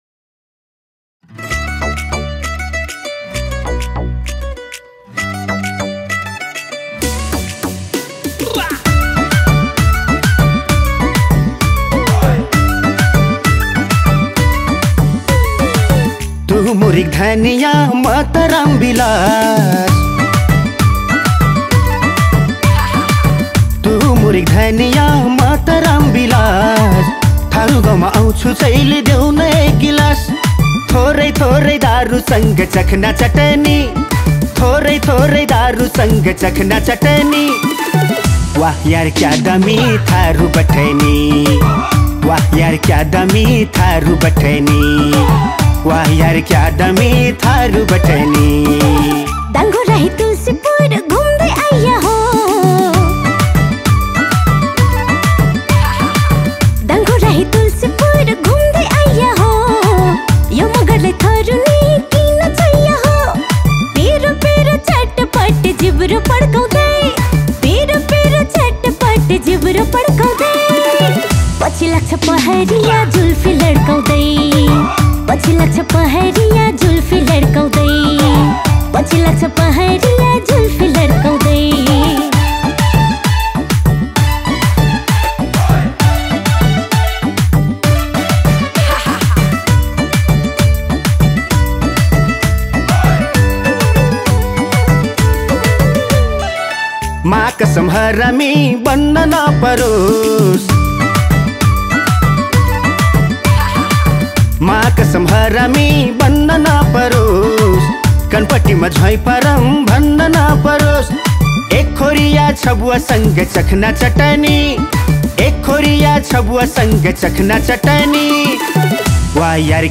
soulful voices